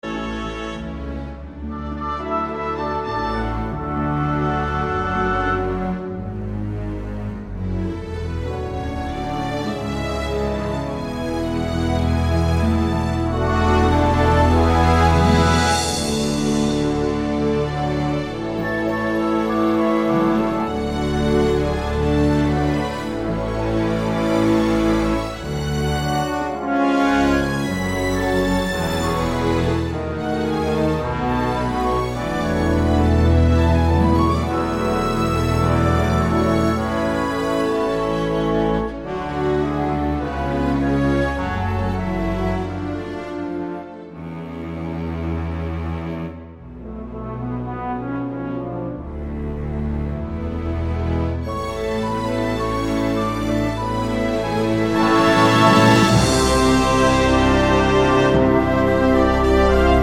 With Female Singer Soundtracks 2:16 Buy £1.50